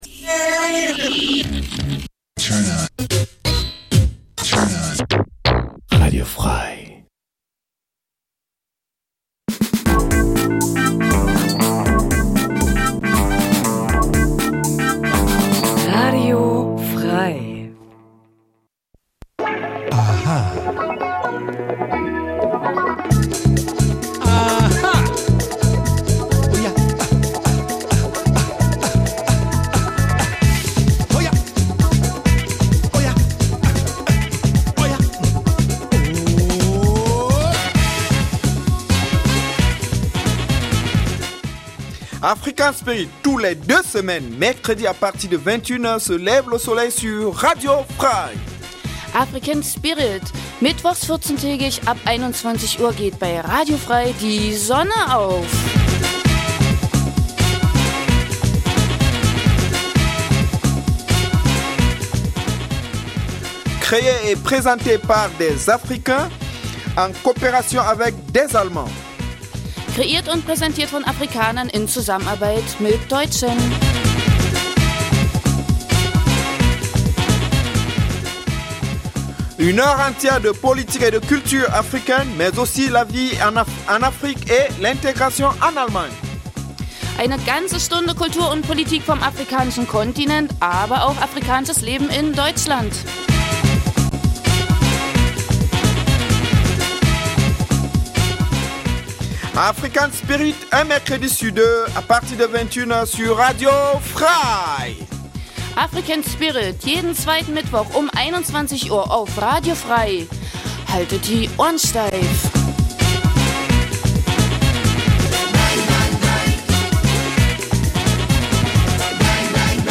Afrikanisches zweisprachiges Magazin Dein Browser kann kein HTML5-Audio.
Die Gespräche werden mit afrikanischer Musik begleitet.